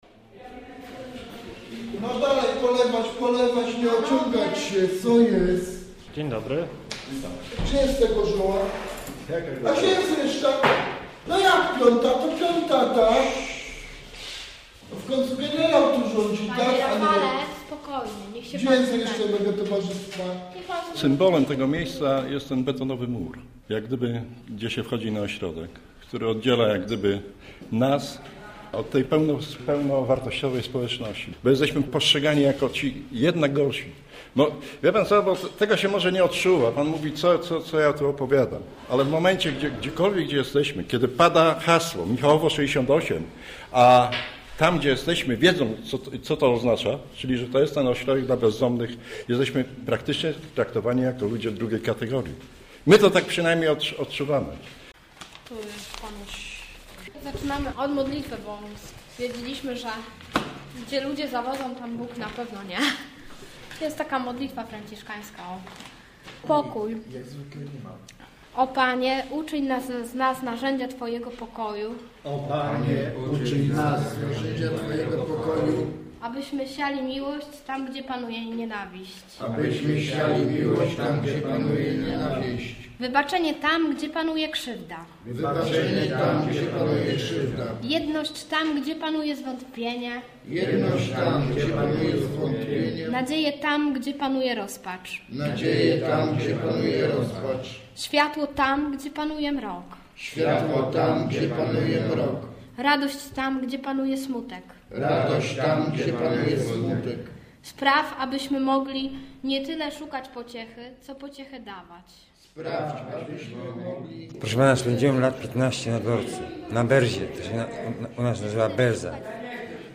Michałowo 68 - reportaż